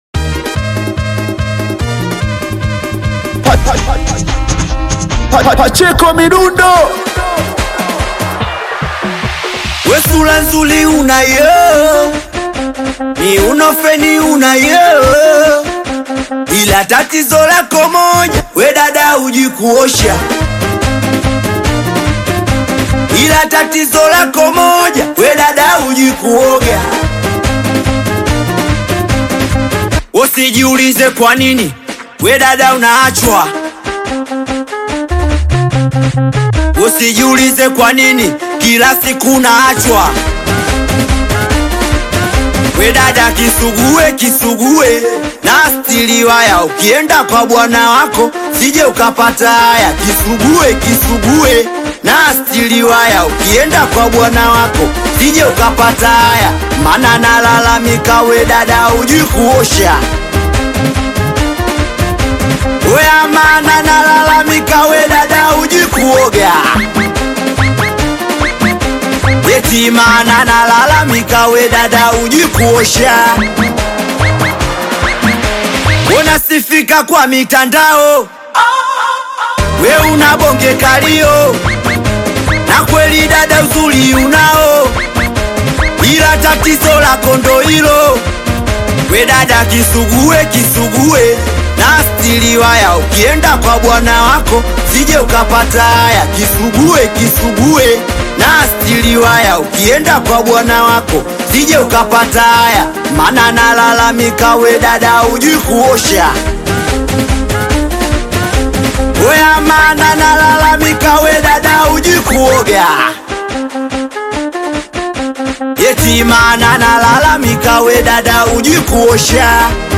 Tanzanian Bongo Flava singeli
Singeli